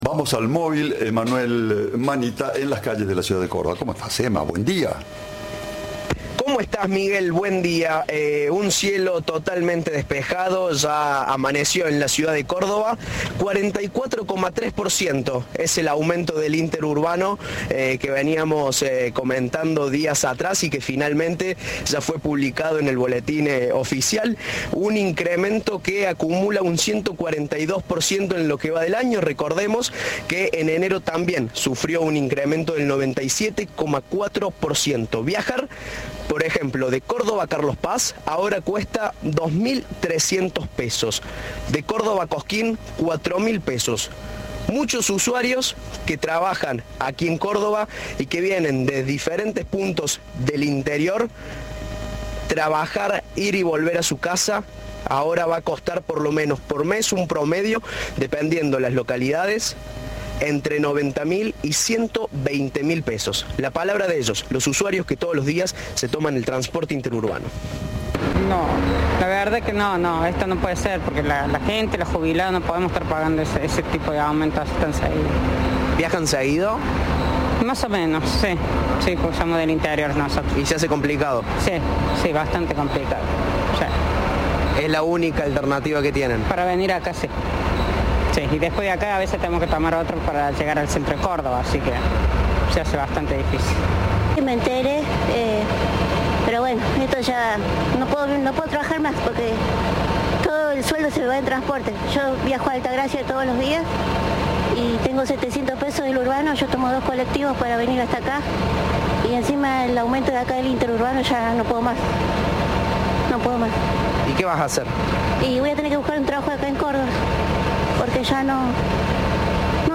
El testimonio de los usuarios
Informe